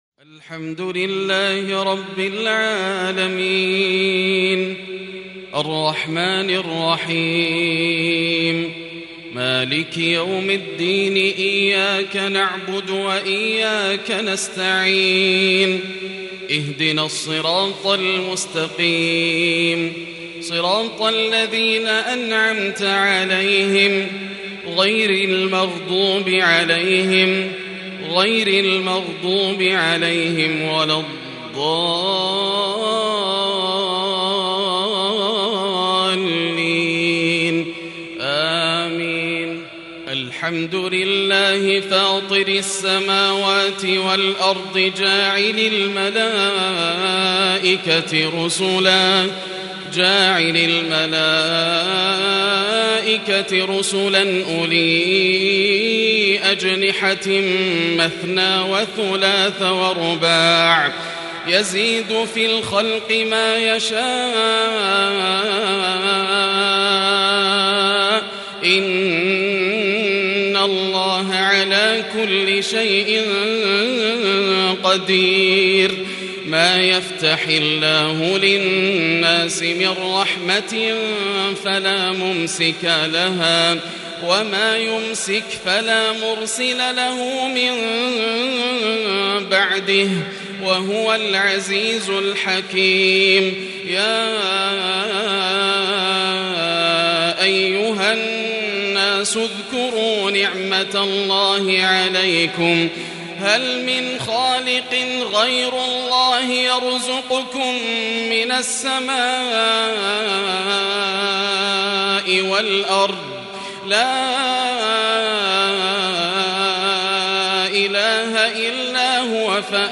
صلاة الفجر للشيخ ياسر الدوسري يوم الثلاثاء ٦-١-١٤٤٢ هـ سورة فاطر | Fajr prayer from Surat Fatir 25/8/2020 > 1442 🕋 > الفروض - تلاوات الحرمين